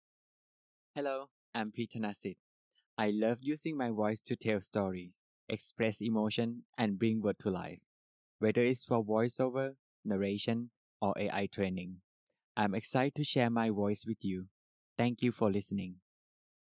Narrative Demo
Storytelling voice sample
Narrative voice demonstration showcasing storytelling abilities and voice modulation.